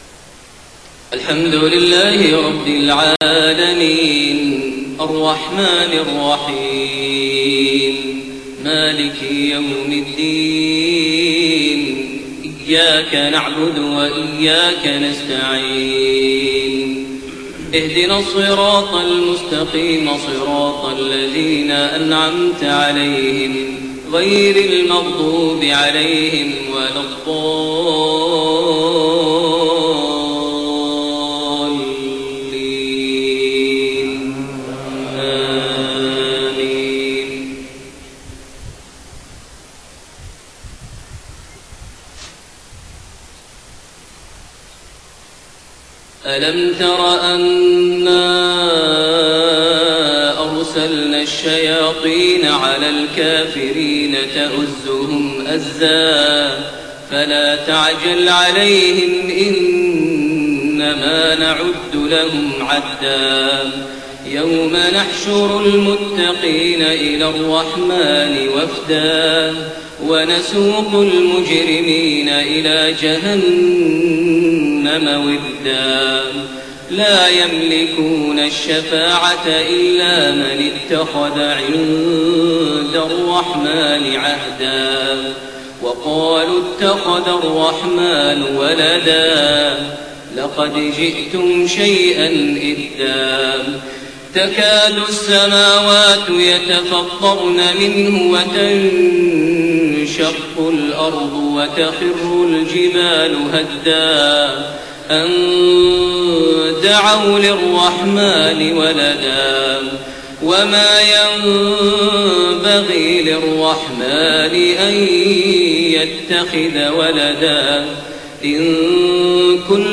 صلاة المغرب1-6-1432 من سورة مريم 83-98 > 1432 هـ > الفروض - تلاوات ماهر المعيقلي